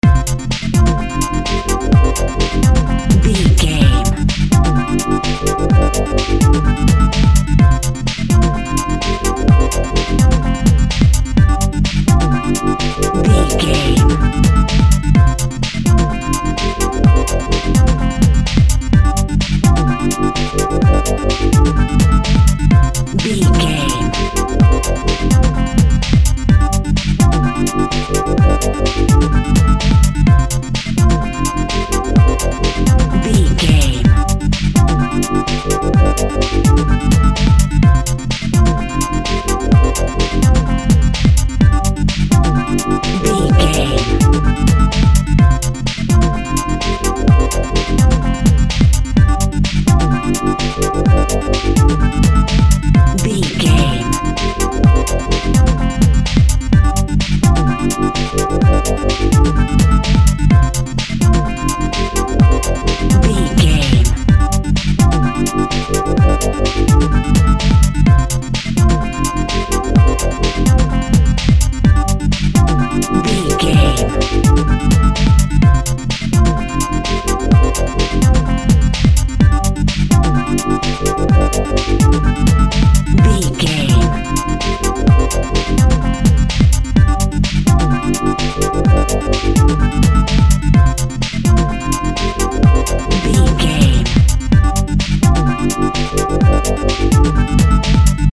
Ionian/Major
groovy
high tech
uplifting
futuristic
driving
energetic
repetitive
drum machine
synthesiser
Drum and bass
break beat
electronic
sub bass
synth lead